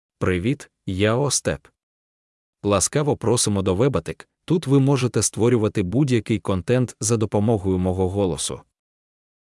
Ostap — Male Ukrainian AI voice
Ostap is a male AI voice for Ukrainian (Ukraine).
Voice sample
Listen to Ostap's male Ukrainian voice.
Male
Ostap delivers clear pronunciation with authentic Ukraine Ukrainian intonation, making your content sound professionally produced.